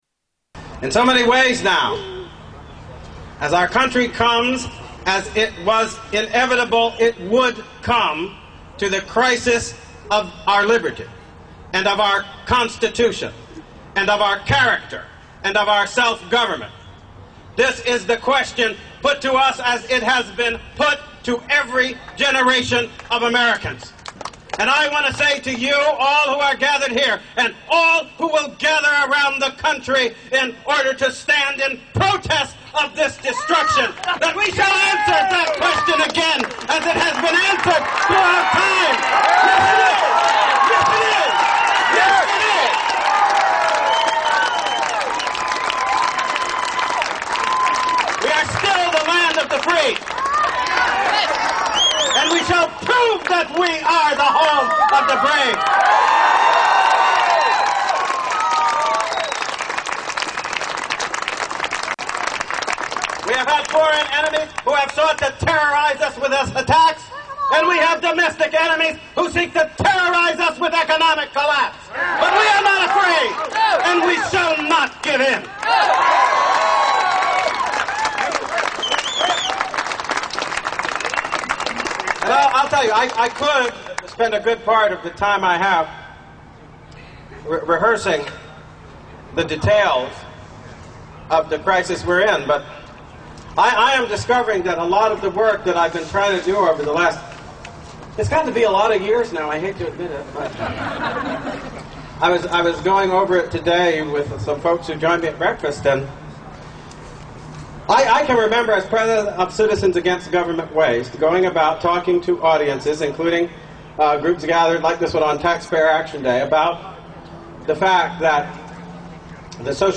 Alan Keyes' Speech-April 11, 2009
Tags: Political Alan Keyes audio Alan Keyes Alan Keyes Speeches The Tea Part